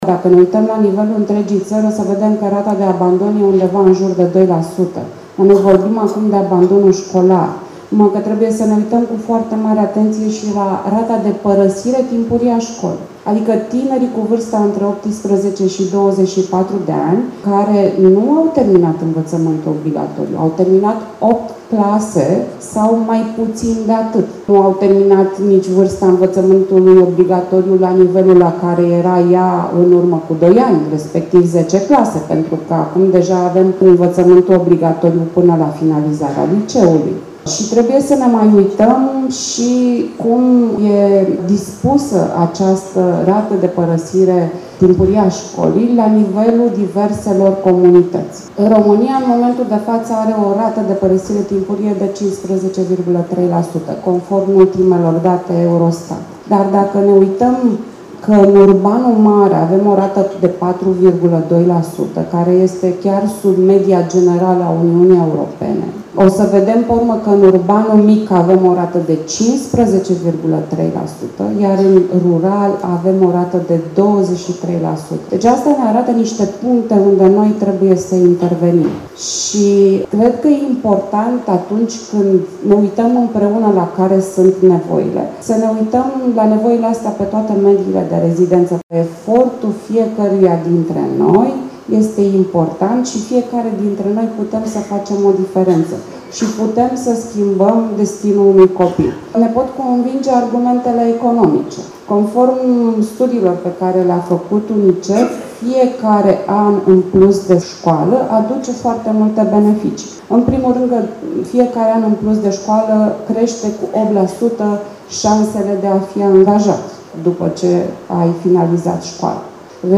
Abandonul școlar – o criză extrem de gravă cu care se confruntă România, a fost tema dezbaterii organizată ieri, la Botoșani, de Confederația Națională pentru Antreprenoriat Feminin (CONAF), în cadrul proiectului național „Pactul pentru Tineri”, în parteneriat cu Ministerul Educației, Ministerul Familiei, Tineretului și Egalității de Șanse, Ministerul Muncii și Solidarității Sociale, autoritățile locale, Banca Mondială și UNICEF.